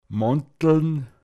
schlendern, langsam gehen måntln